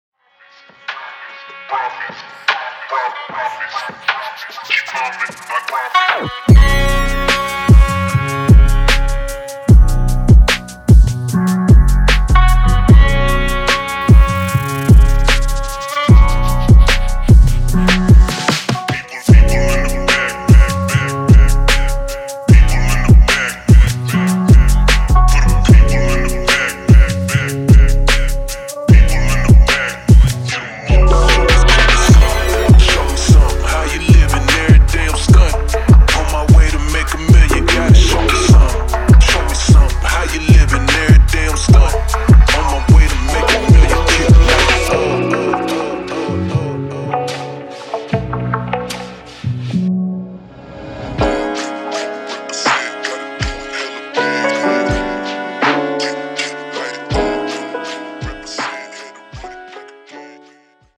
Hip-Hop